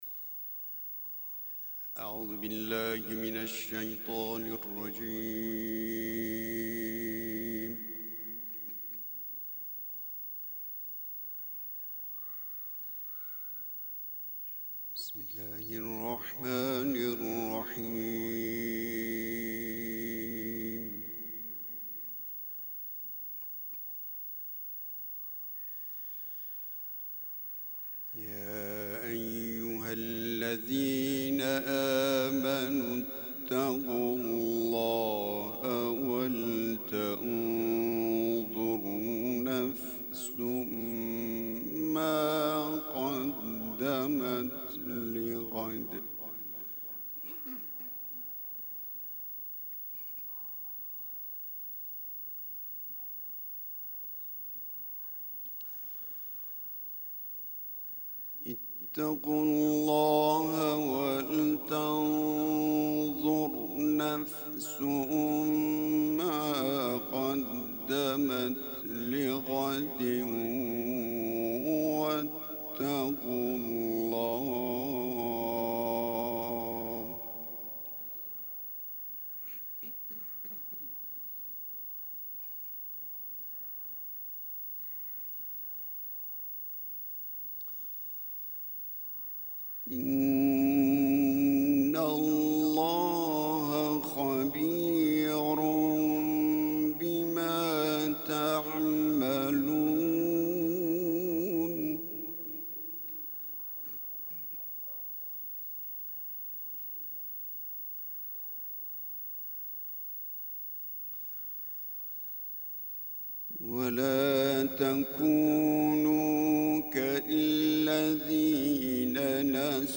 تلاوت ظهر
تلاوت قرآن کریم